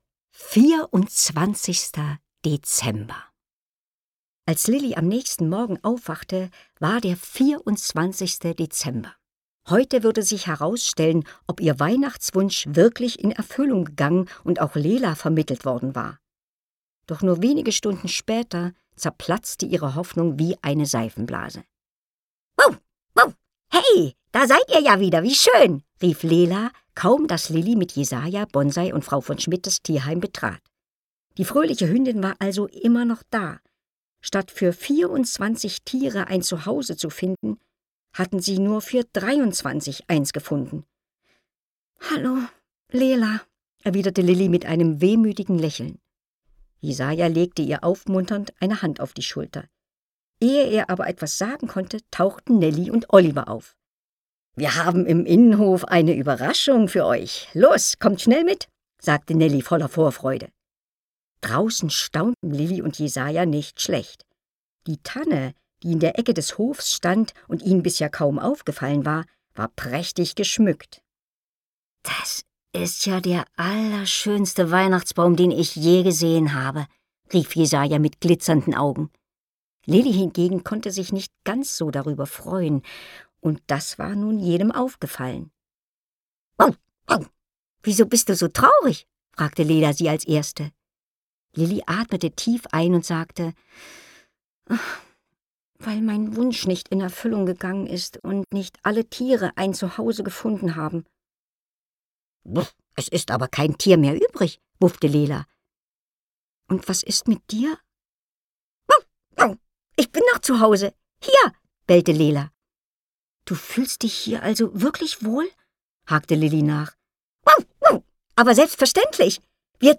Hörbuch: Liliane Susewind – 24 Tiere suchen ein Zuhause.